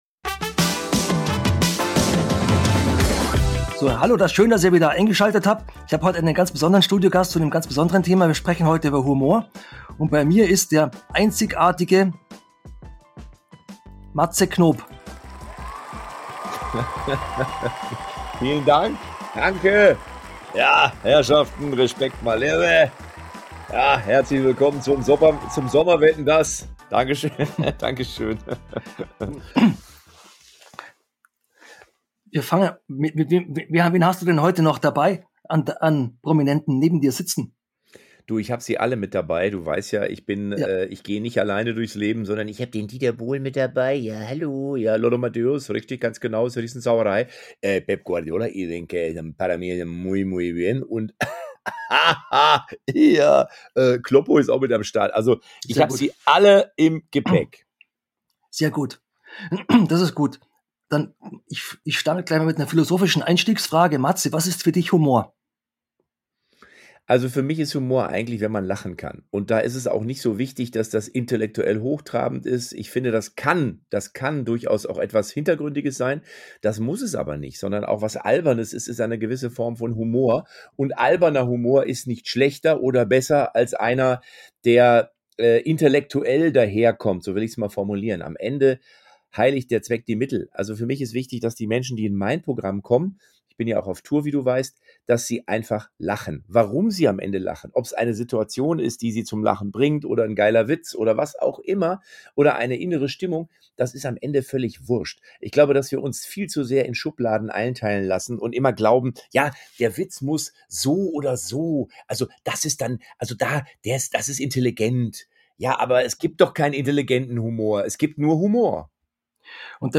In dieser Folge macht Dir mein Gast, der Comedian Matze Knop, mit Dir einen unterhaltsamen Ausflug in die Welt des Humors